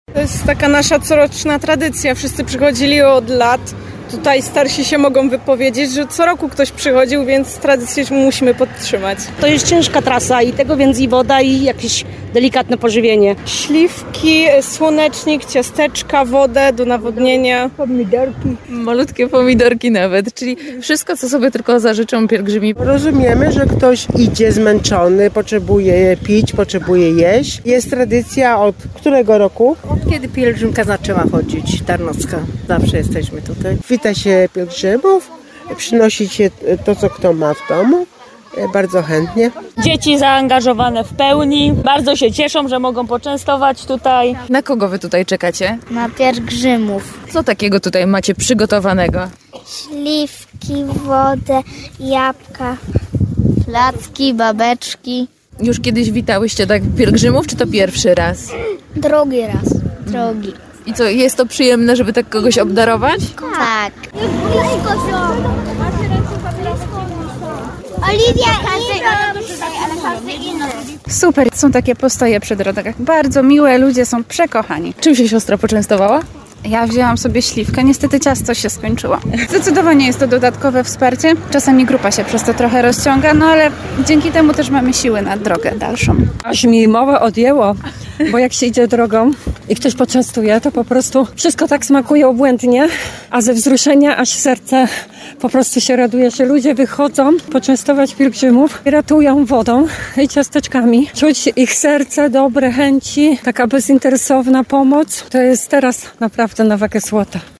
– mówili lokalni mieszkańcy i Koło Gospodyń Wiejskich z miejscowości Bessów i parafii Cerekiew .